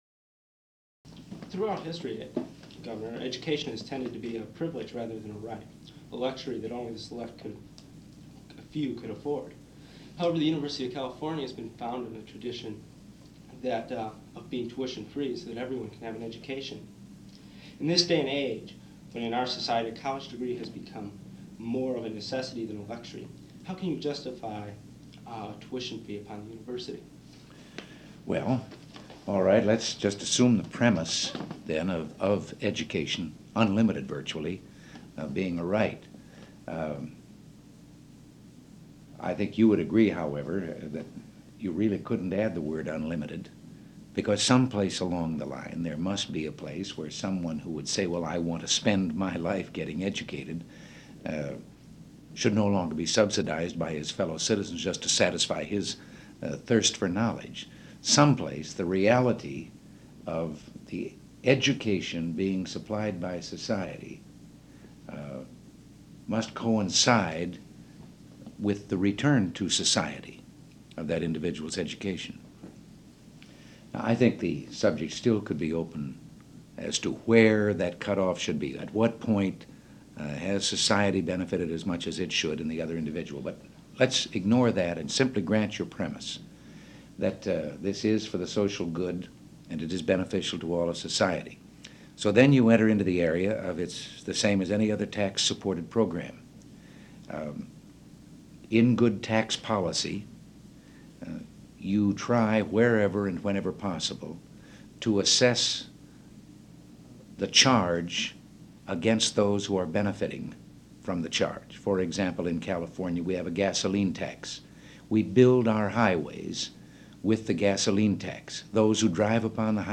Location Sacramento, California